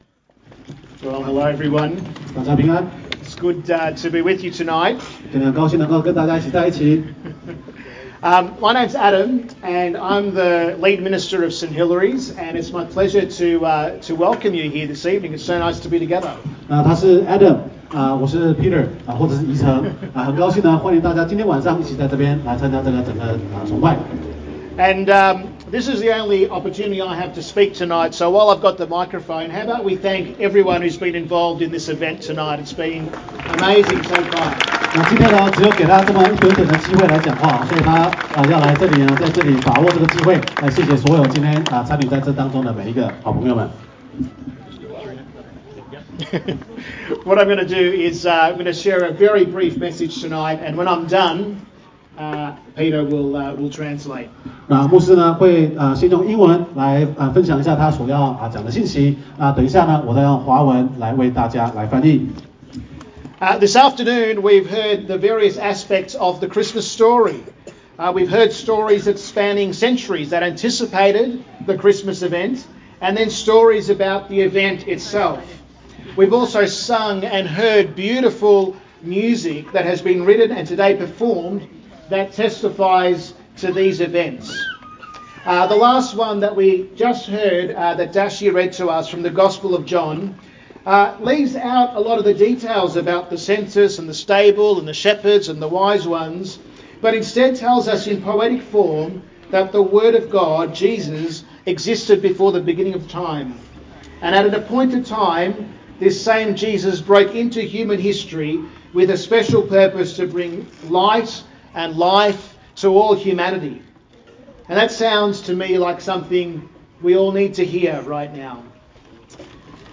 Christmas Festival Talk (Bilingual - English & Mandarin)